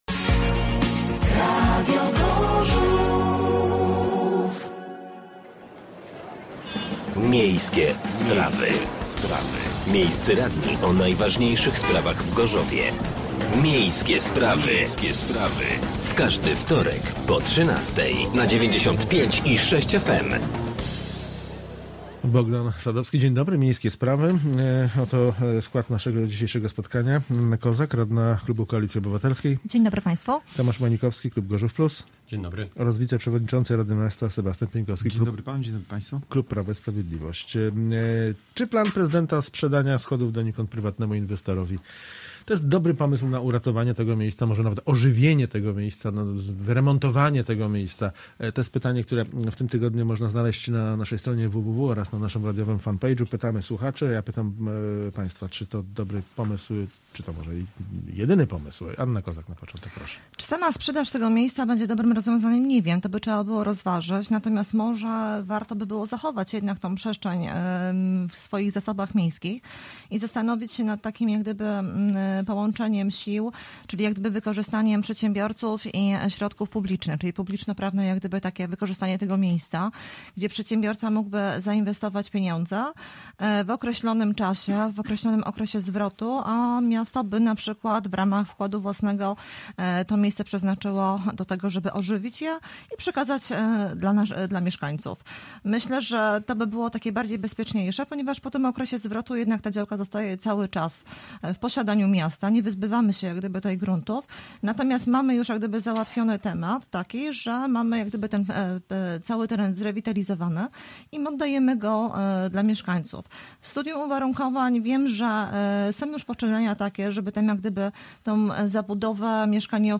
Gośćmi byli radni: Anna Kozak (Koalicja Obywatelska) . Sebastian Pieńkowski (Prawo i Sprawiedliwość) , Tomasz Manikowski (Gorzów Plus)